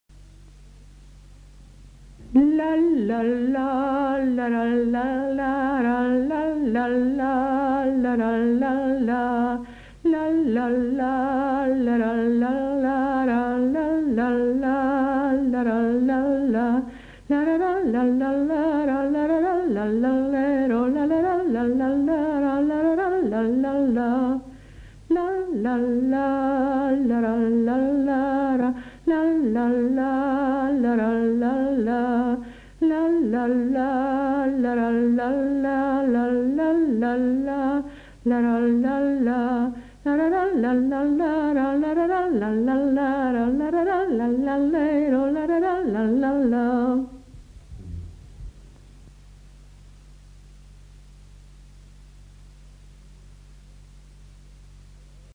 Aire culturelle : Haut-Agenais
Genre : chant
Effectif : 1
Type de voix : voix de femme
Production du son : fredonné
Danse : scottish-valse